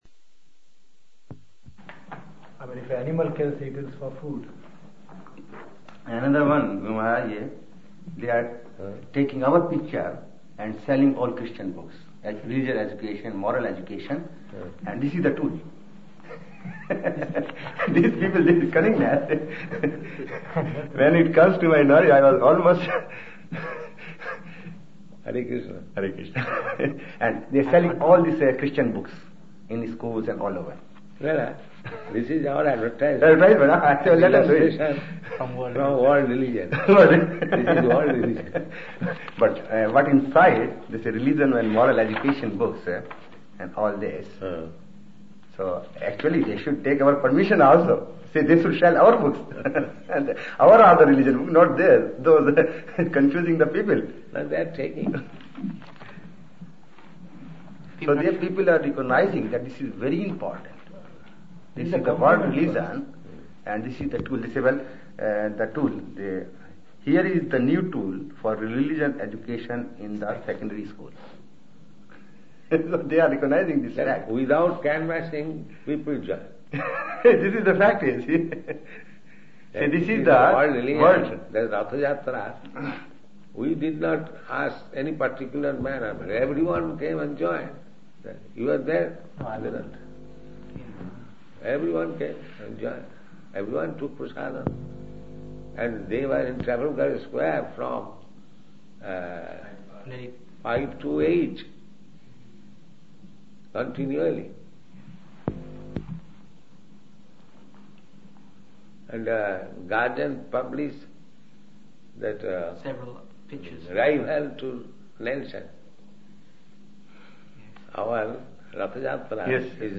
Srila Prabhupada Conversation, London – July 14, 1973